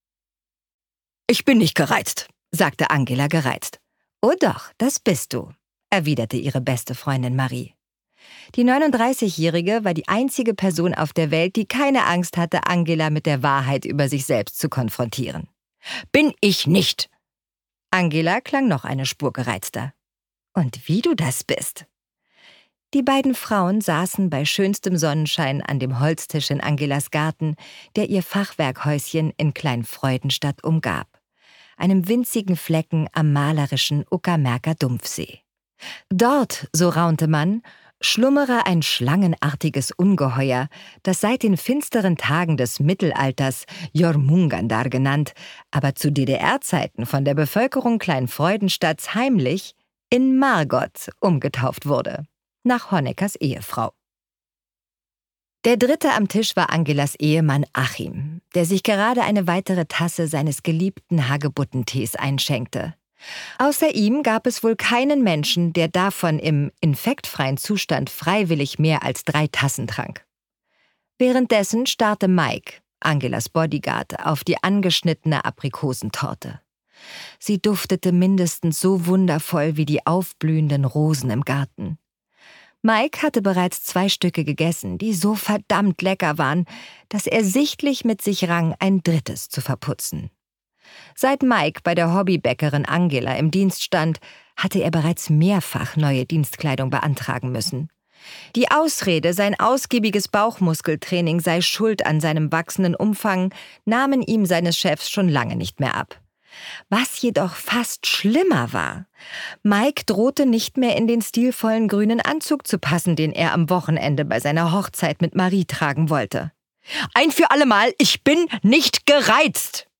Miss Merkel: Mord in der Therapie David Safier (Autor) Nana Spier (Sprecher) Audio Disc 2024 | 2.